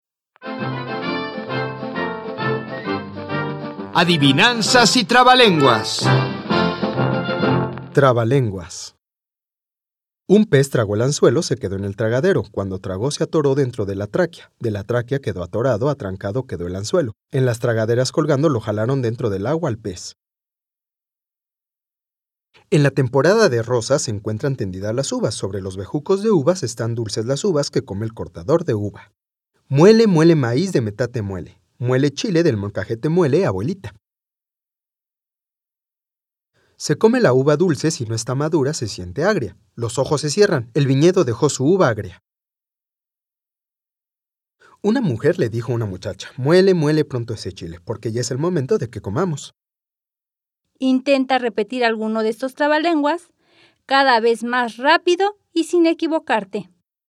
Audio 5. Trabalenguas
112_Trabalenguas.mp3